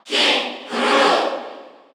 Category: Crowd cheers (SSBU) You cannot overwrite this file.
King_K._Rool_Cheer_Korean_SSBU.ogg